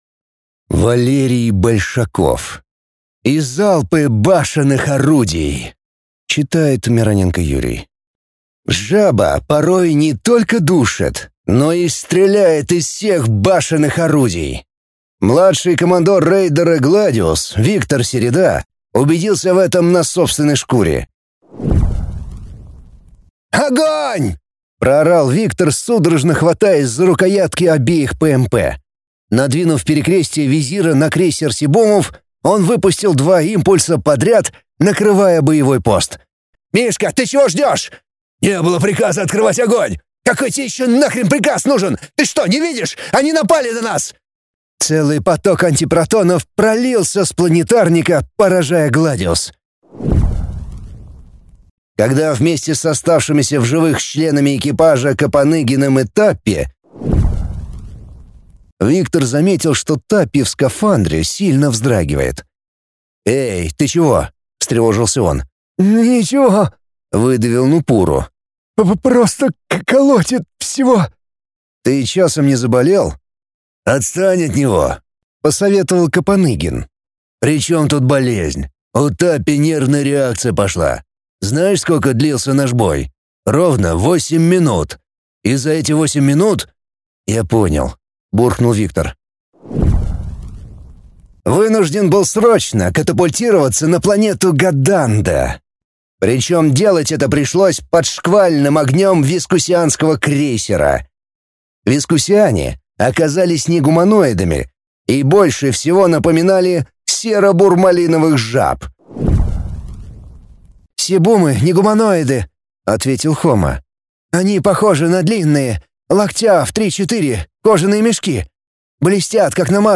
Аудиокнига И залпы башенных орудий…